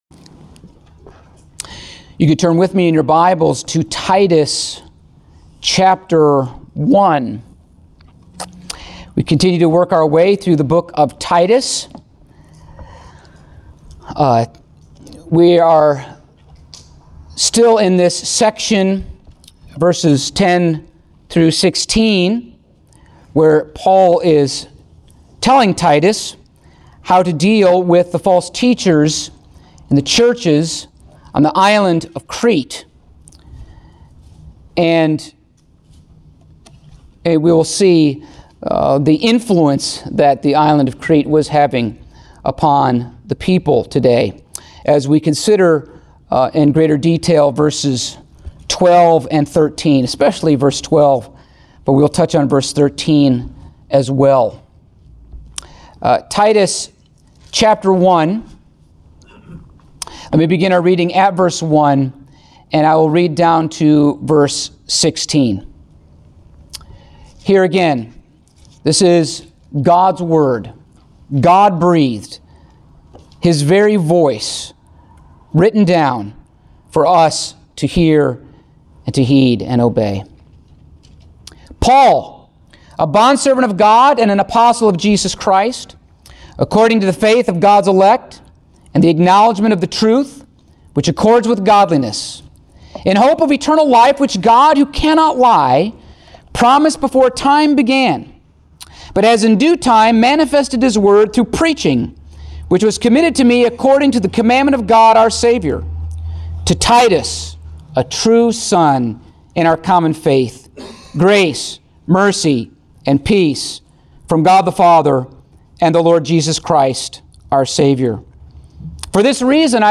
Titus Passage: Titus 1:12-13 Service Type: Sunday Morning Topics